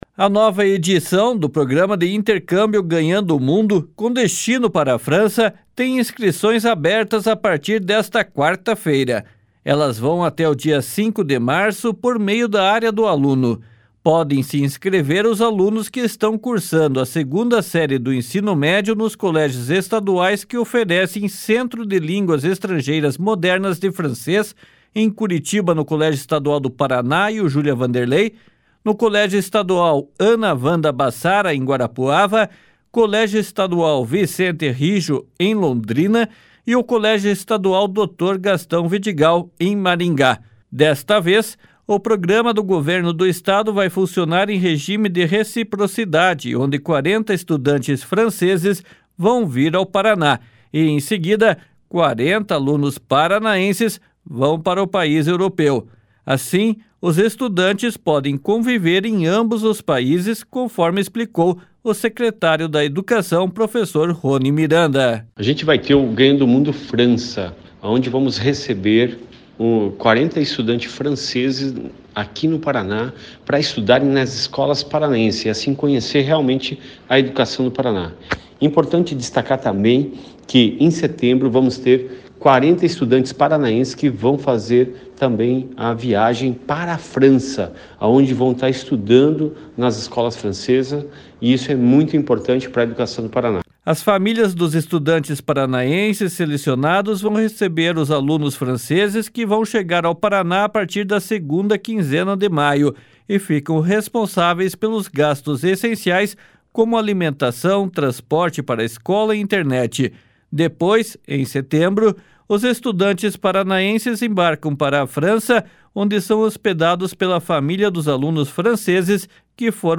//SONORA RONI MIRANDA//